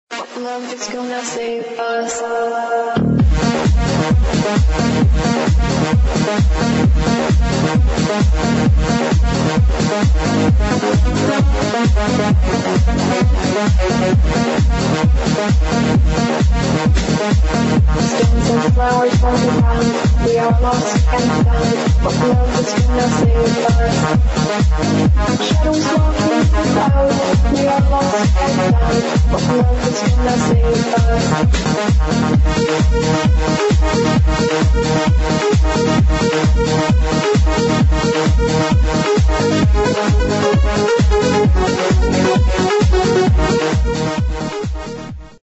[ HOUSE / ELECTRO ]
(Vocal Club Mix)